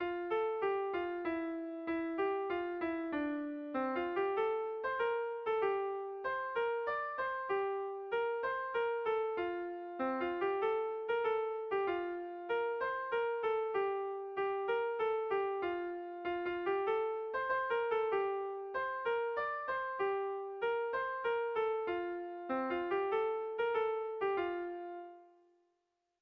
Zortziko handia (hg) / Lau puntuko handia (ip)
ABDB